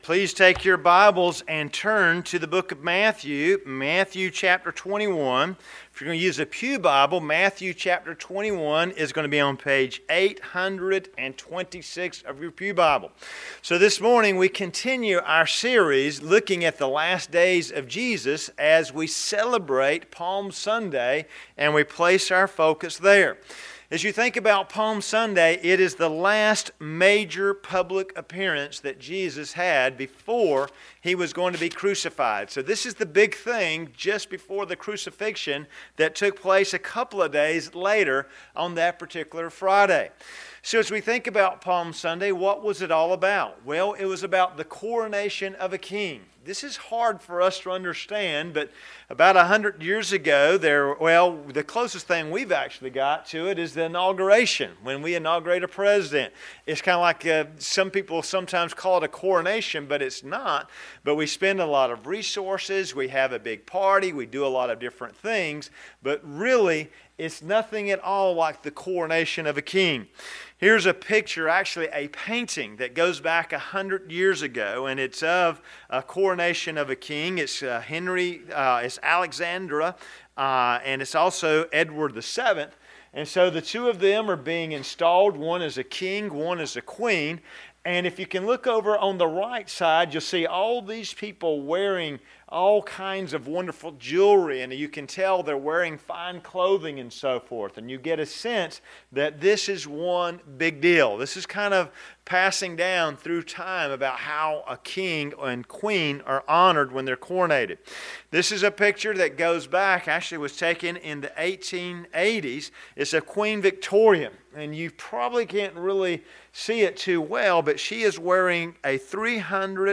Sermons - Tippett's Chapel Free Will Baptist Church